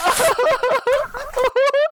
manlylaugh